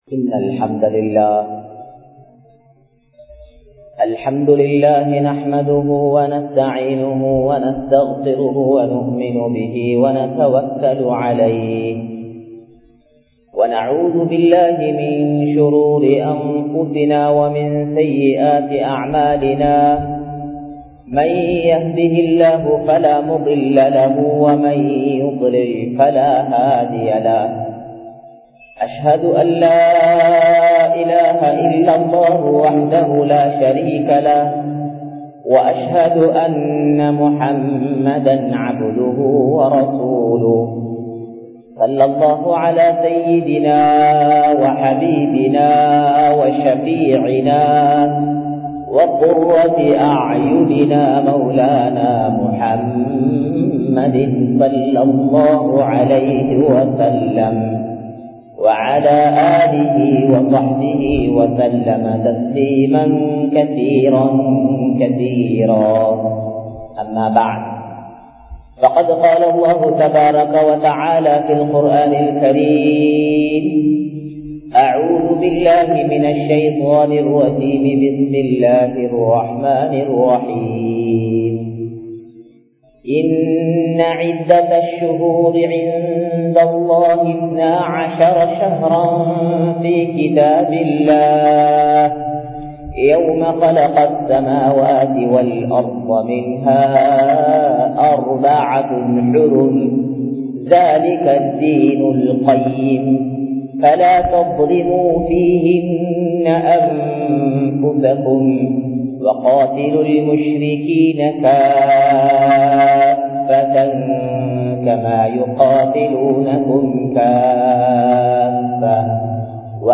முஹர்ரம் மாதத்தின் சிறப்புகள் (Highlights of the Month Muharram) | Audio Bayans | All Ceylon Muslim Youth Community | Addalaichenai